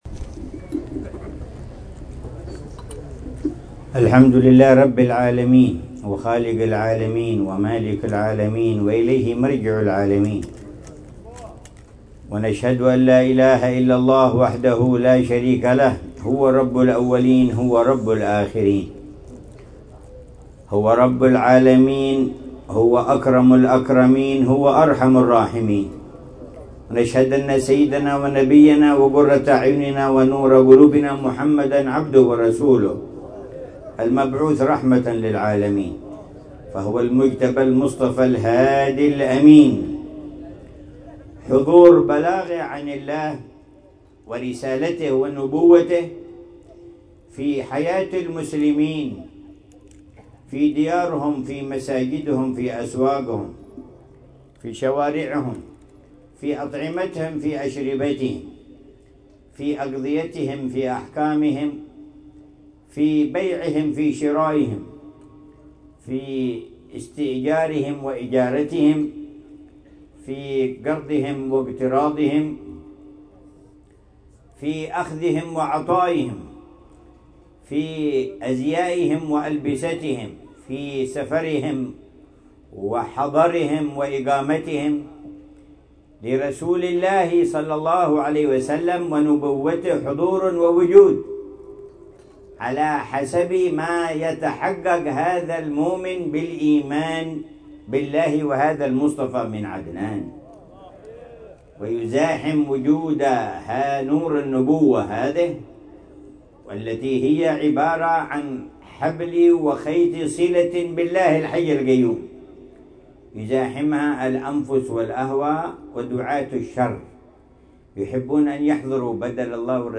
مذاكرة العلامة الحبيب عمر بن محمد بن حفيظ في الاحتفال بذكرى المولد النبوي الذي نظمه أصحاب المحلات التجارية في سوق تريم ليلة الأربعاء 22 ربيع الأول 1446هـ بعنوان: